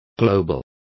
Complete with pronunciation of the translation of global.